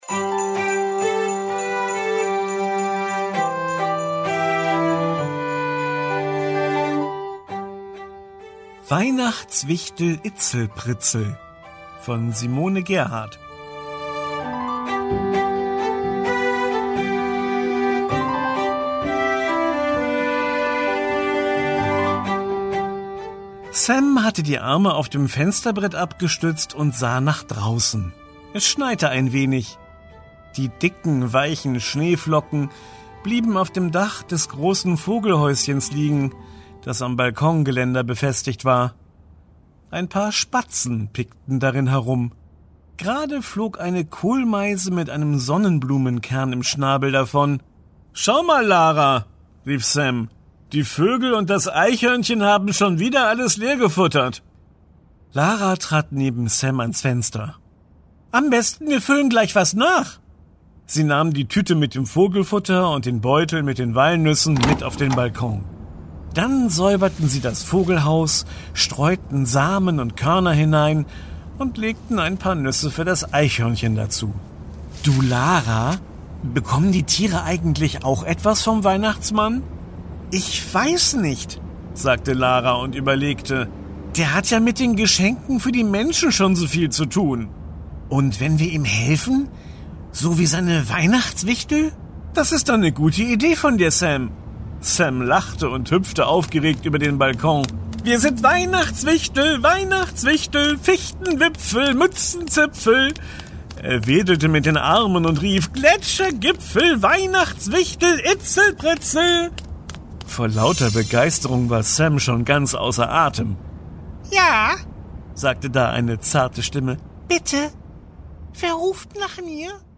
Vorlesebücher und Hörbuch-Geschichten
Für Tiger-Media habe ich zwei Geschichten geschrieben, die eingelesen und in den tigertones Hör-Adventskalendern veröffentlicht wurden.